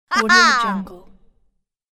دانلود افکت صوتی خنده برنده شدن کوتاه یک زن
Female Win Laugh royalty free audio track is a great option for any project that requires human sounds and other aspects such as a sfx, laughter and sound.
Sample rate 16-Bit Stereo, 44.1 kHz
Looped No